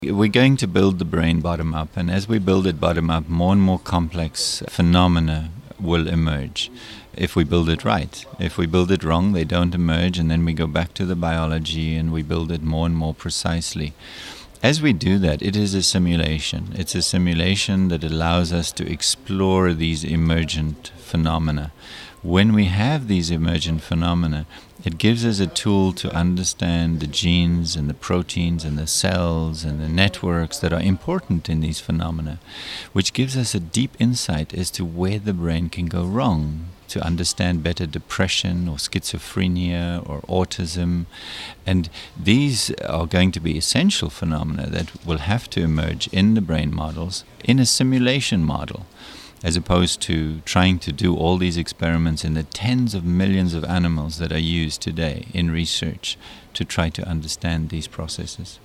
Henry Markram explains how the model will help understanding of the brain.